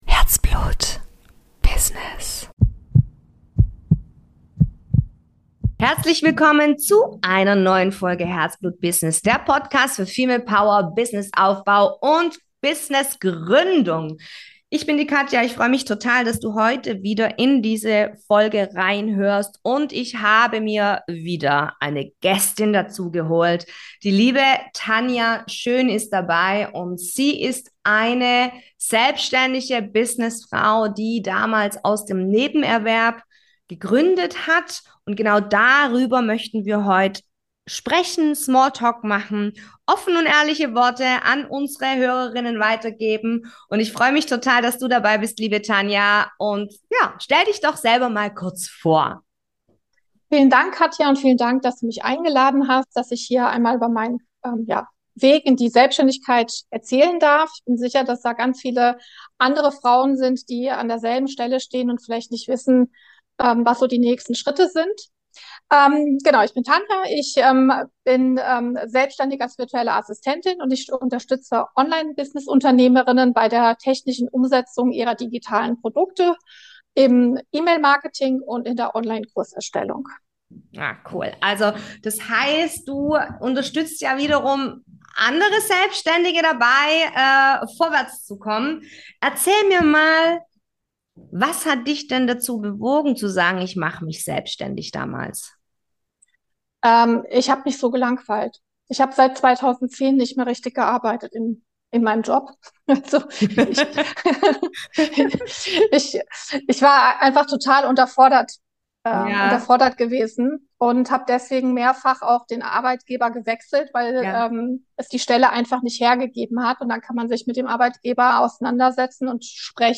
#69 Gründung aus dem Nebenerwerb - Interview